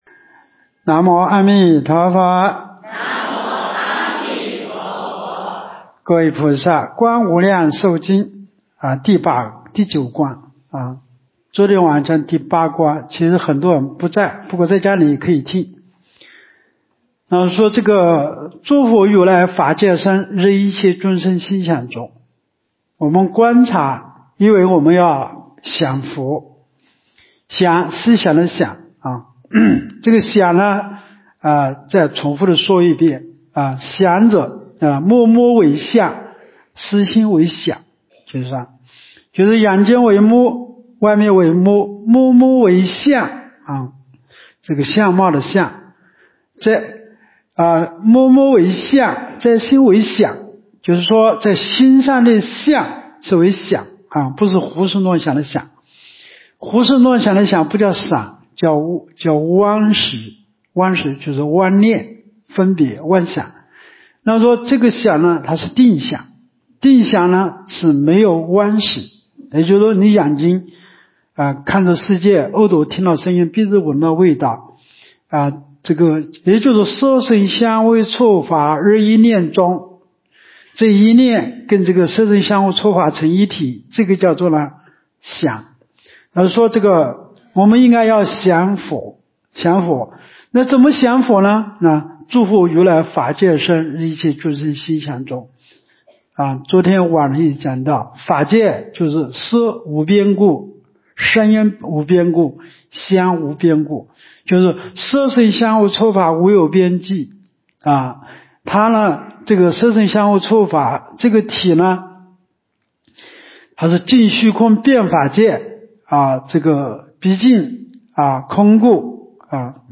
无量寿寺冬季极乐法会精进佛七开示（27）（观无量寿佛经）...